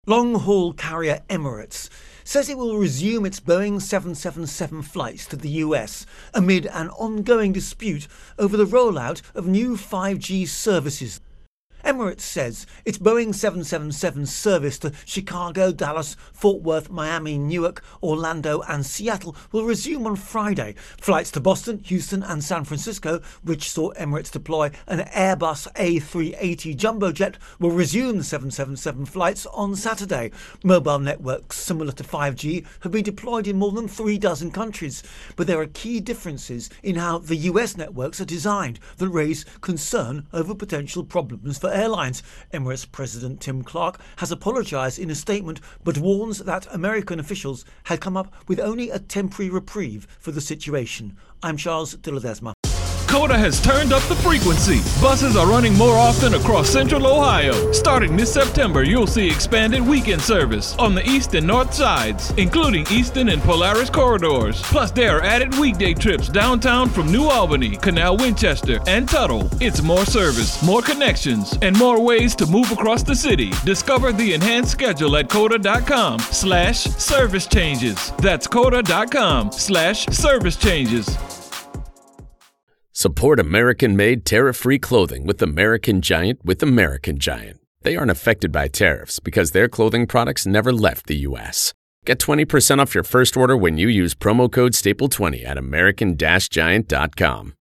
5G-Airlnes Intro and Voicer